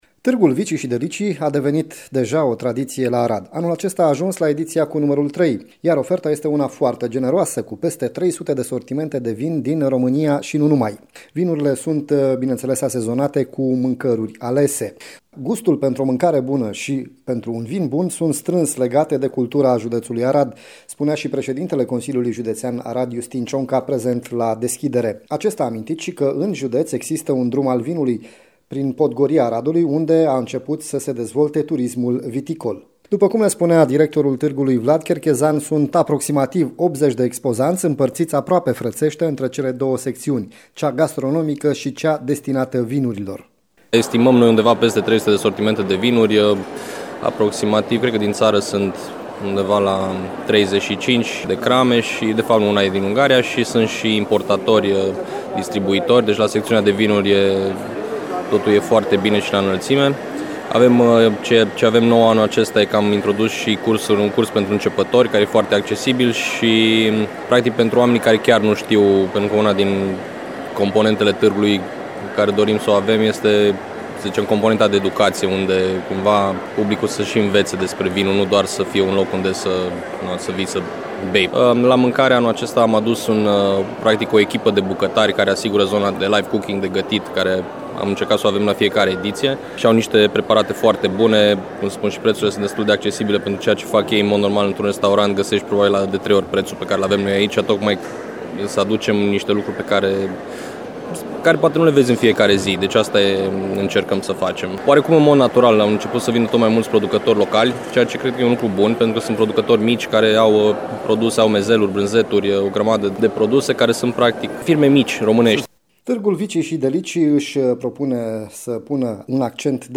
Sute de sortimente de vinuri și spectacole gastronomice la târgul “Vicii și Delicii” de la Arad